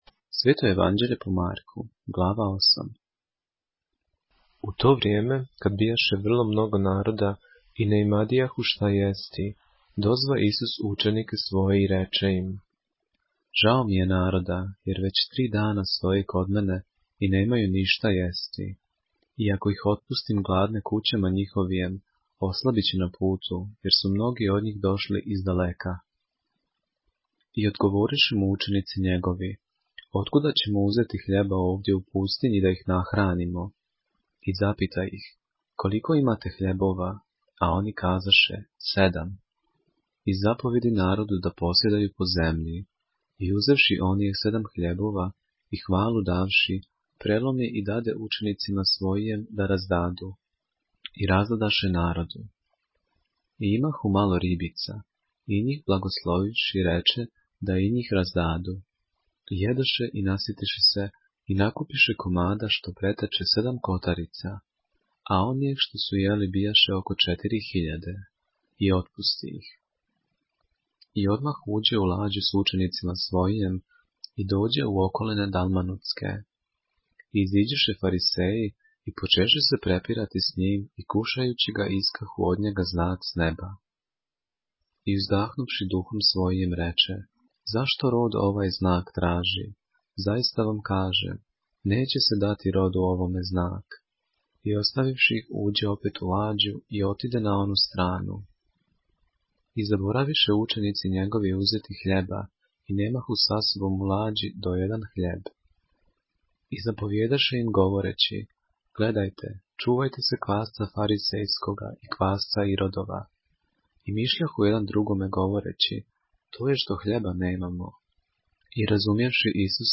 поглавље српске Библије - са аудио нарације - Mark, chapter 8 of the Holy Bible in the Serbian language